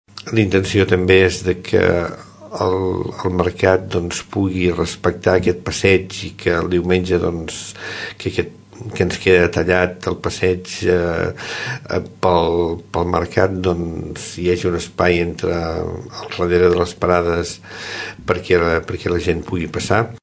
Així ho explicava als micròfons de Ràdio Tordera, el regidor d’Obres i Serveis, Carles Aulet.